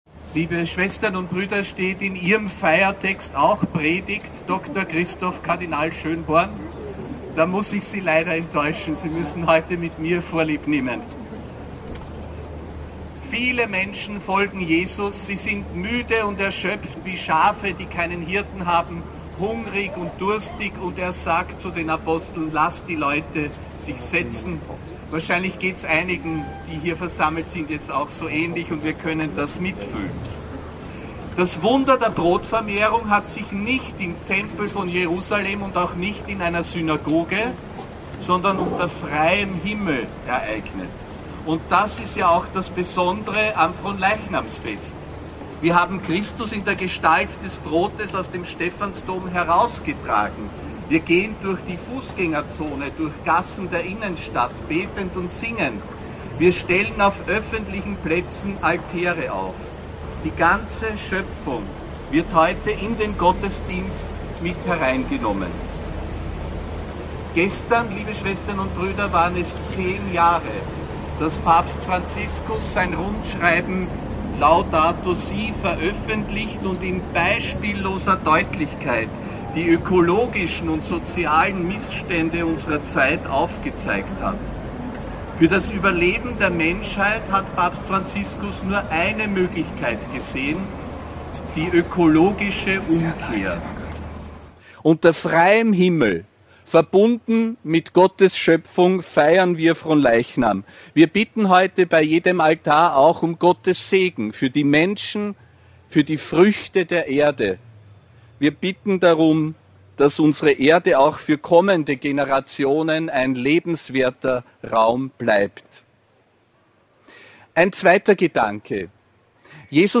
Predigt von Josef Grünwidl zu Fronleichnam (19. Juni 2025)
Predigt des Apostolischen Administrators Josef Grünwidl zu